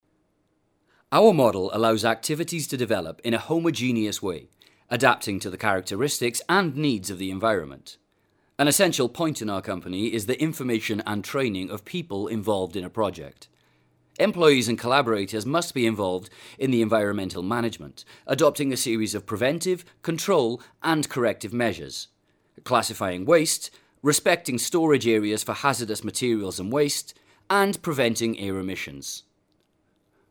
locutor ingles, English voice over